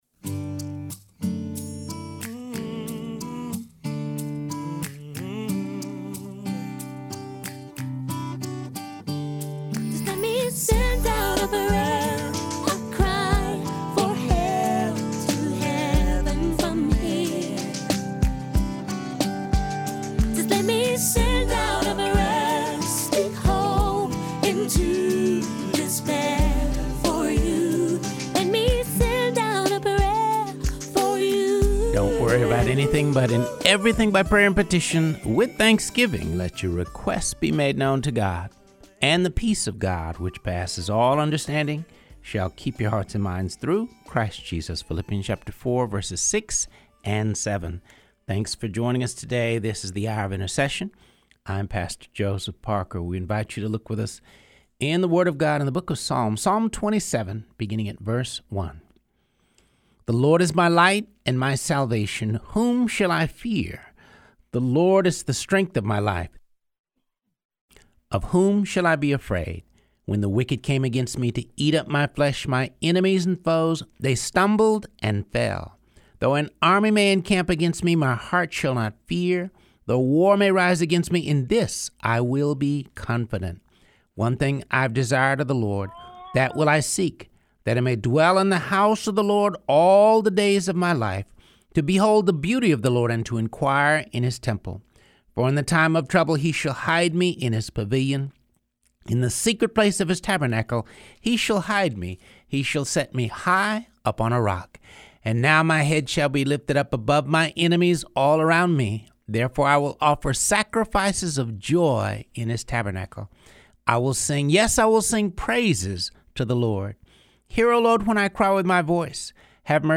(The interview begins at 11:30.)